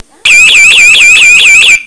SIRENE PIEZOELÉTRICA COM SINALIZAÇÃO VISUAL
Potência audível: Emite um som potente de 120dB a 1 metro, garantindo a audibilidade em situações de emergência;
120dB @1 metro